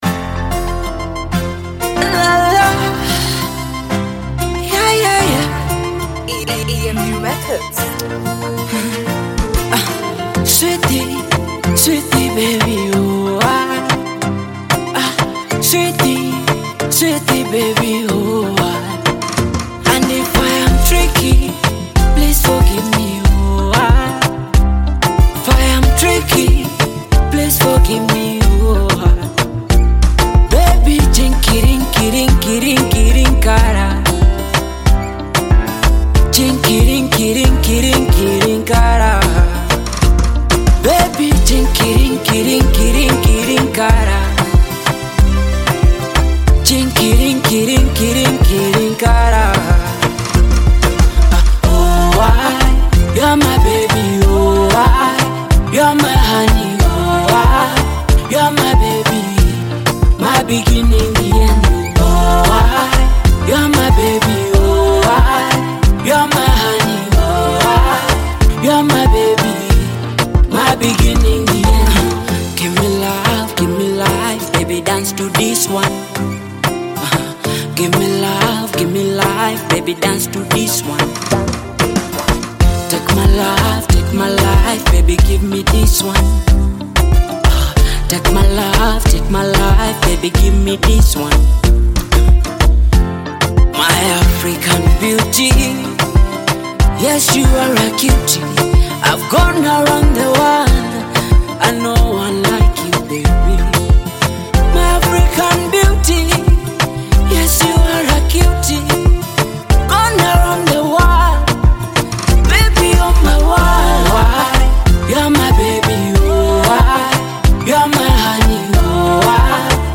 Kenyan artist singer and songwriter
Love song
African Music